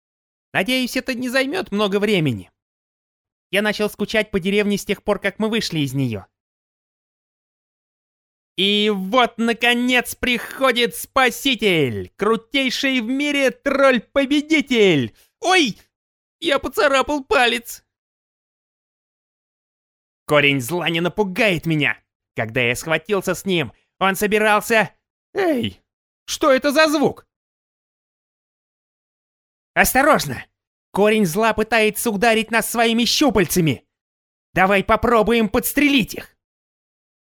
Native Russian voice talent, warm multipurpose voice, great for audiobooks, webinars, commercials, characters VO and many many more
Sprechprobe: Sonstiges (Muttersprache):